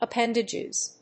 発音記号
• / ʌˈpɛndɪdʒɪz(米国英語)
• / ʌˈpendɪdʒɪz(英国英語)